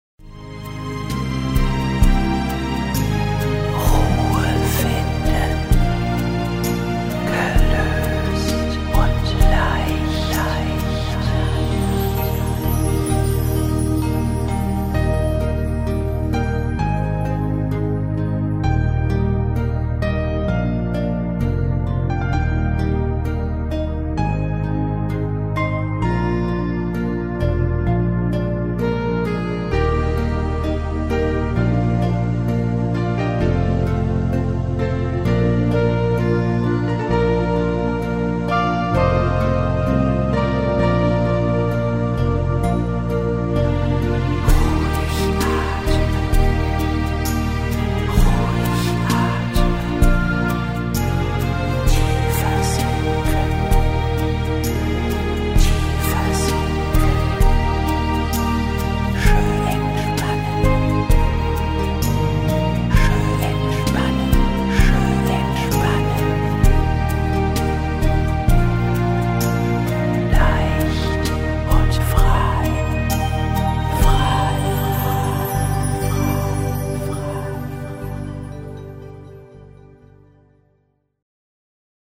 Schwebende Klänge